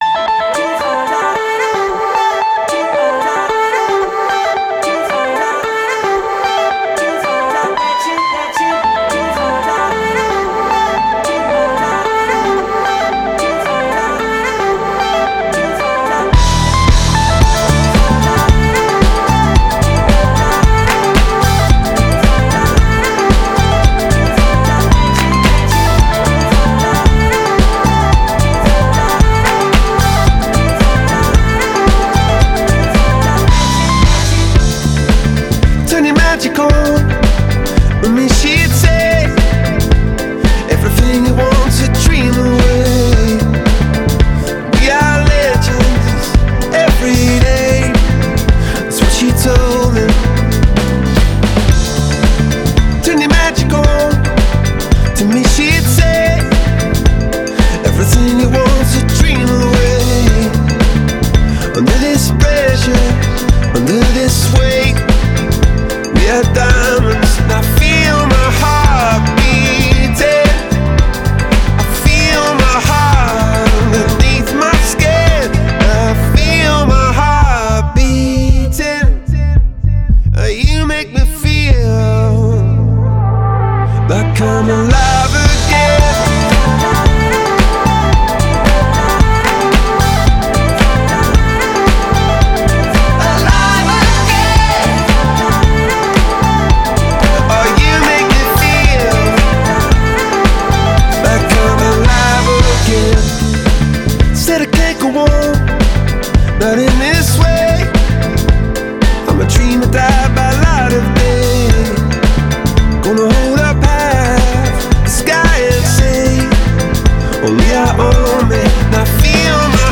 bizarre disco